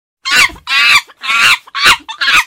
Download Free Scary Gorilla Tag Sound Effects